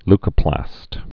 (lkə-plăst) also leu·co·plast (lkə-plăst)